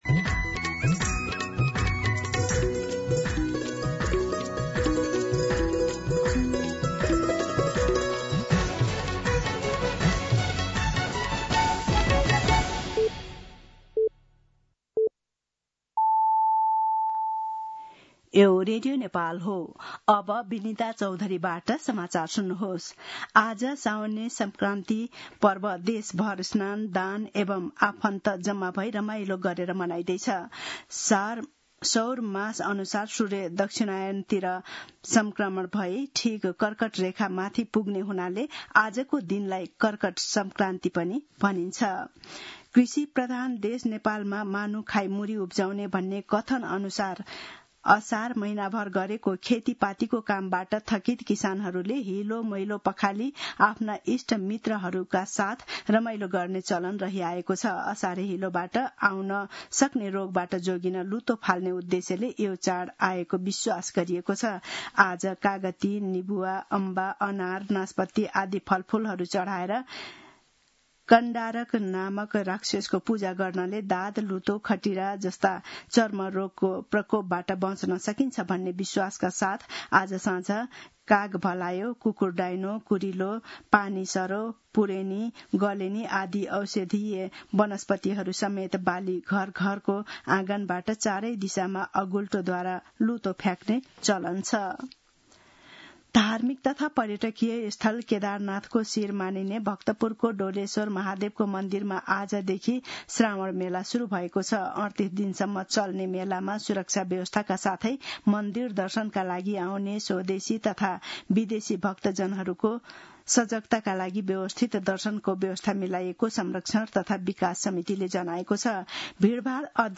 मध्यान्ह १२ बजेको नेपाली समाचार : १ साउन , २०८२